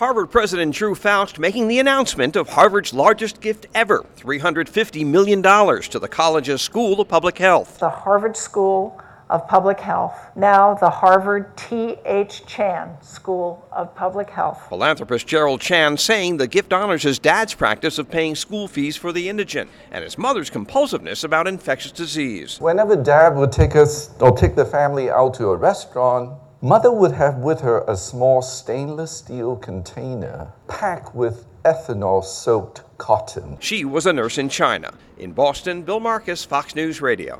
REPORTS FROM BOSTON.